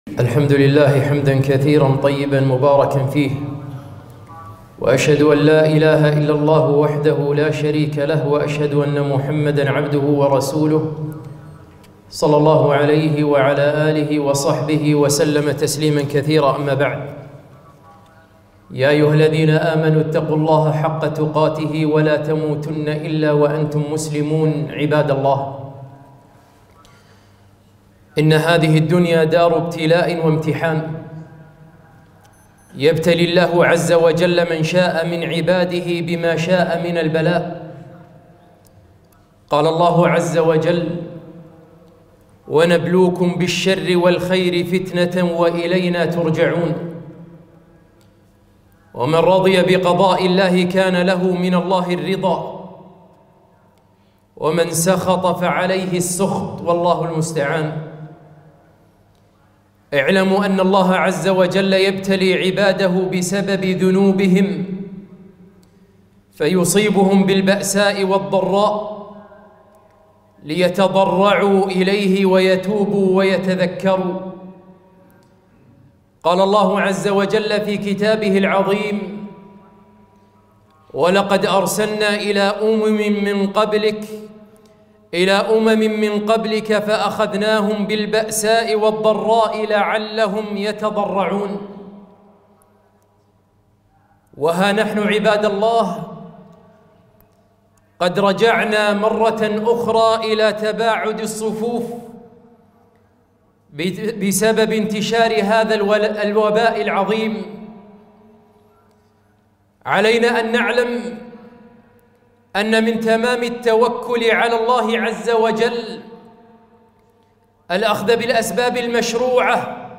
خطبة - ها قد رجعنا للتباعد مرة أخرى!.